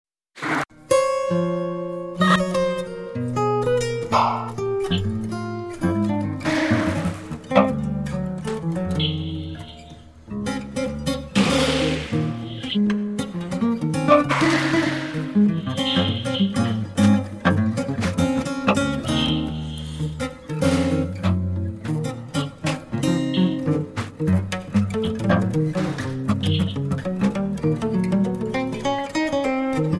Acoustic, Electric Guitar and SuperCollider
Acoustic, Electric Guitar and Percussion